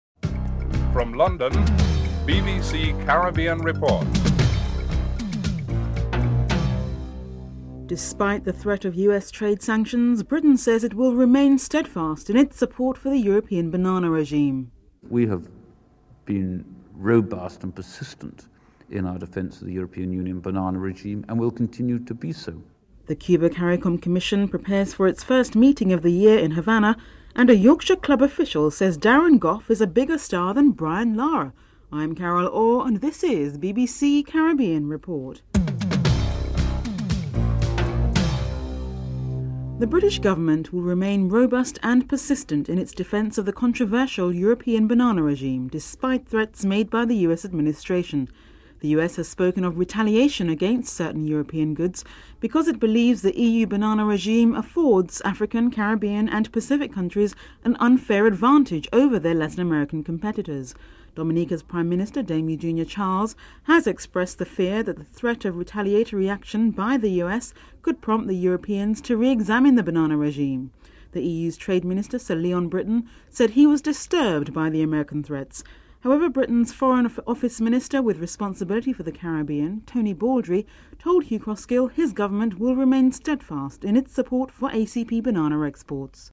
3. Interview with Tony Baldry on the British position on the banana regime (01:27-02:21)
8. Interview with Tony Baldry on the Dominican Republic as a potential market for British and European businesses (10:30-12:40)